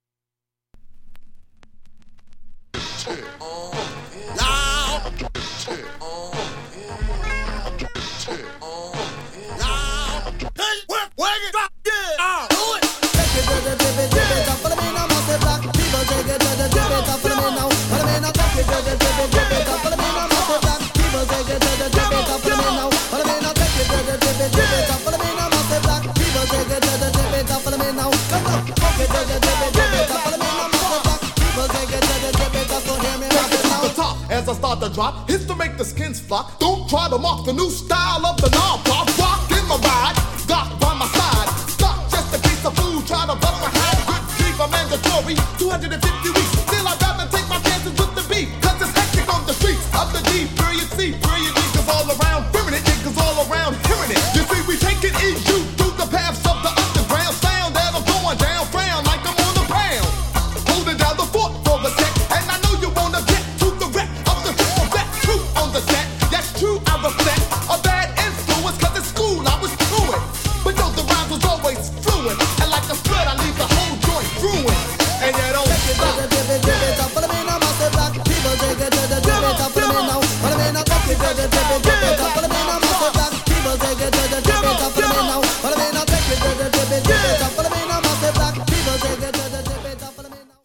Hip Hop Mix